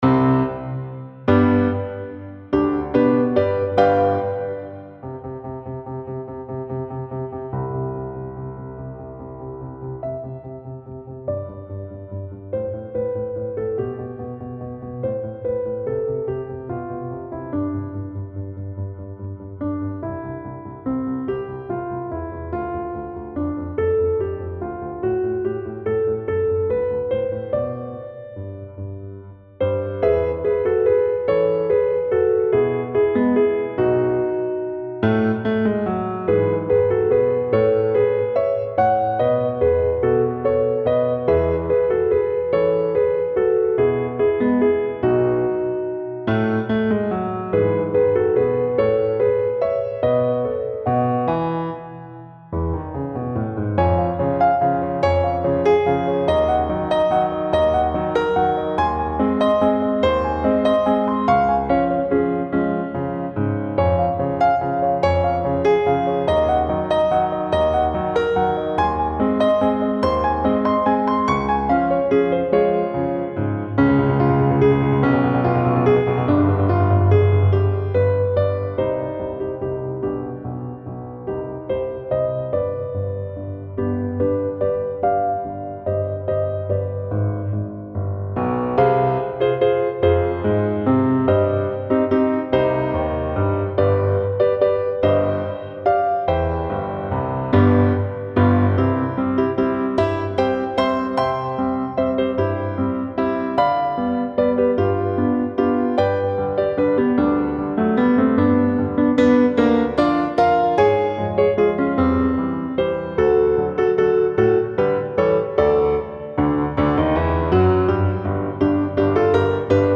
Allegro gracioso, reduced them to what I hope is a manageable piano transcription, and I was done.
Actually, I stole the last bit first, which gave me the opening theme for X. Finale, which I think is pretty fabulous for an opening: That simple, downward-scale theme, with its gently insistent bass line, creates a sense of anticipation, which of course pays off at the very end.
I transposed it down to F major — and then when I decided to steal the penultimate section, that section worked best in the original key of D, which meant I had to tapdance my way into that key change.